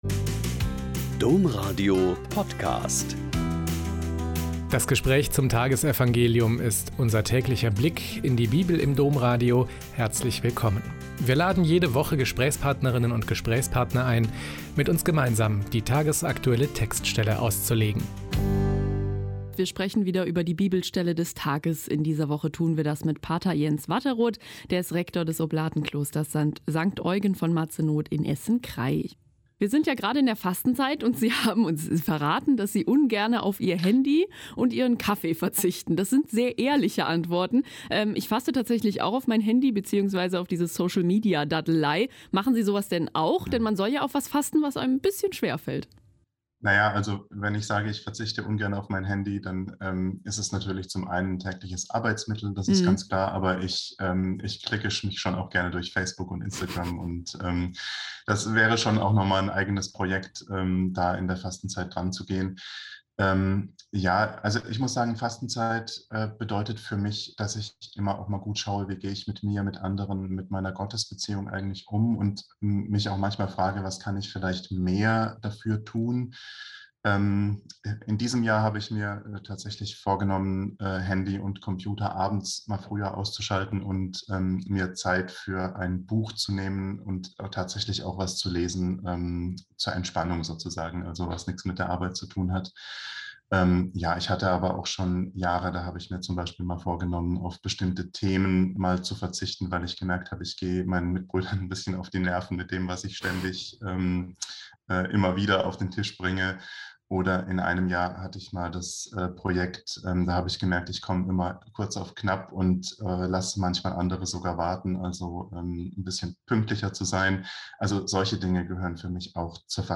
Lk 16,19-31 - Gespräch